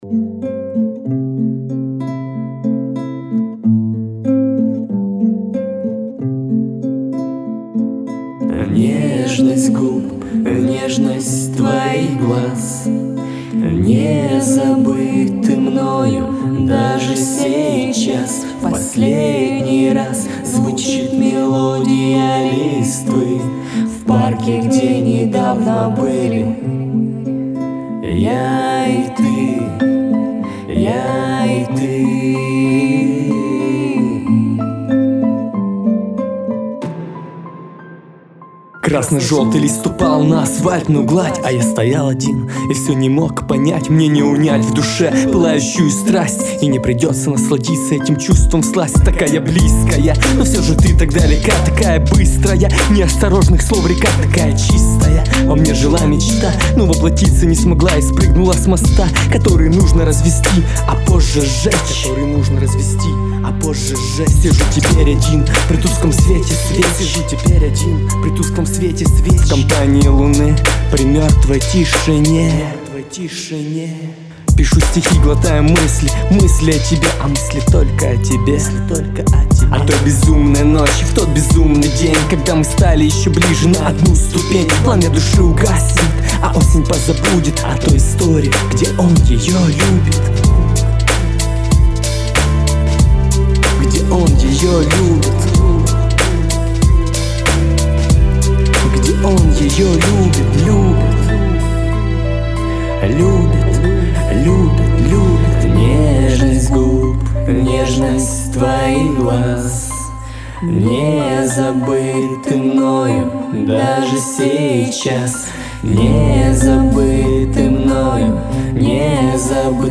Studio Equipment
Marshall Electronics MXL USB.006